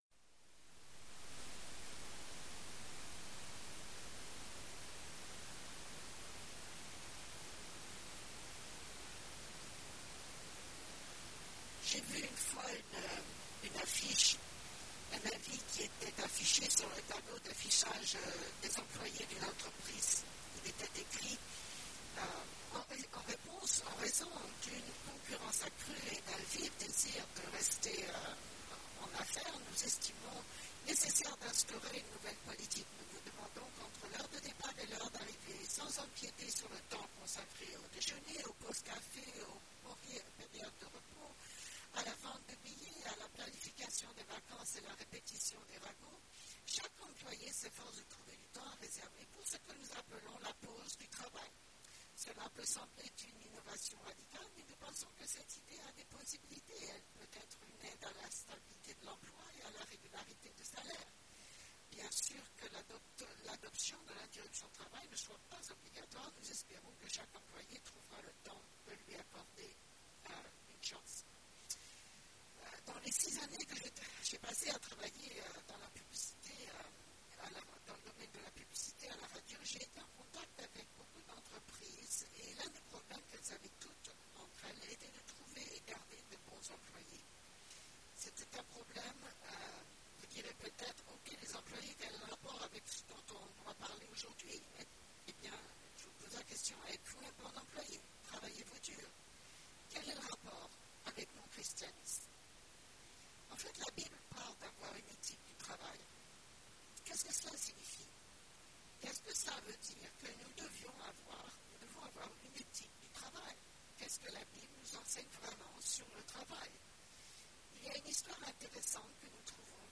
L'éthique chrétienne du travail : avec interprétation simultanée
Dans ce sermon nous examinerons l'enseignement biblique concernant l'éthique du travail pour un chrétien.